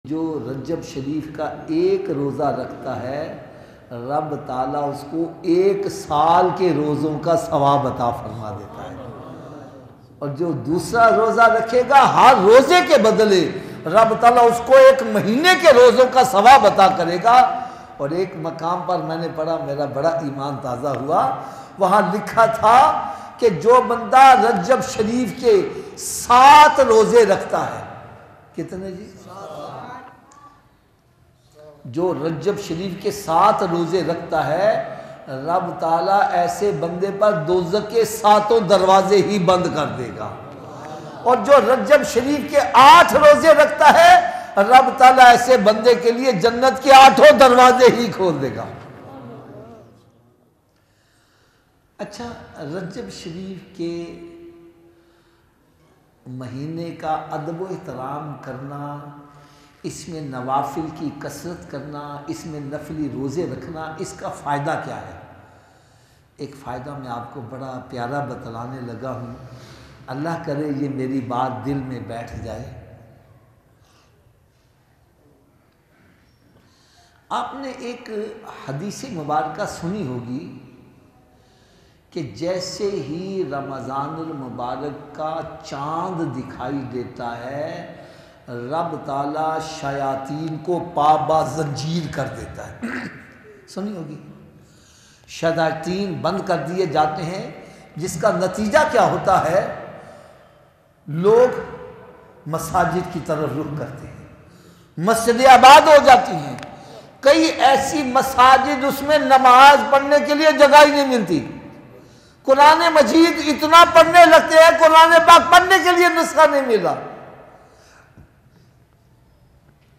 Rajab Ki Fazilat Rajab Ke Roze Ki Fazilat Latest Bayan